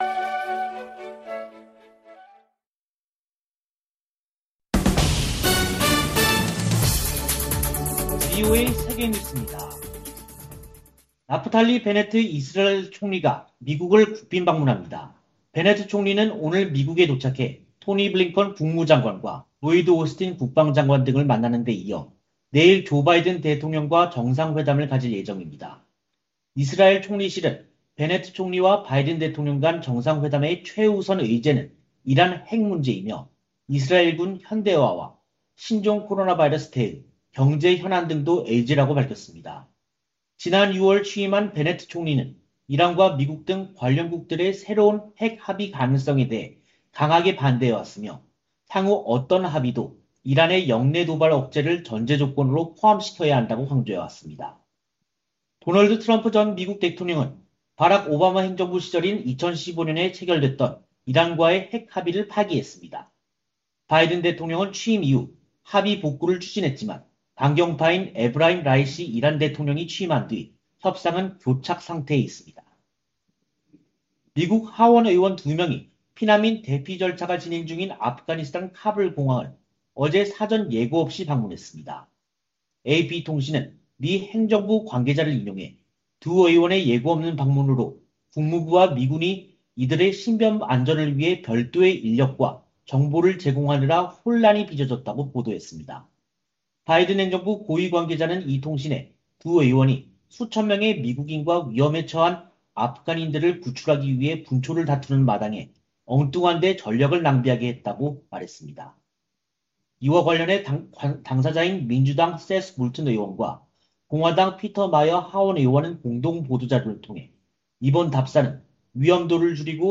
세계 뉴스와 함께 미국의 모든 것을 소개하는 '생방송 여기는 워싱턴입니다', 저녁 방송입니다.